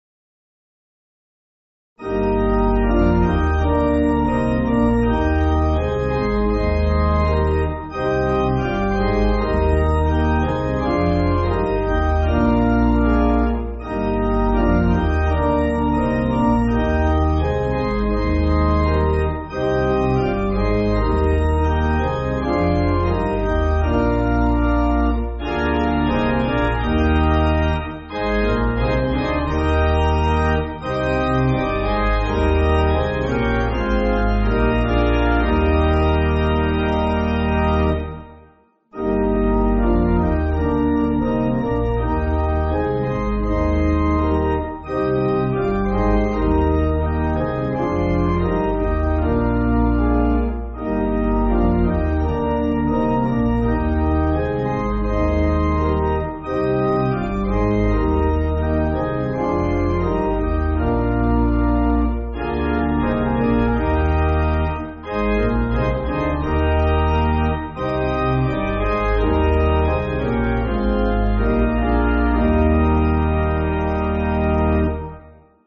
Organ
(CM)   3/Eb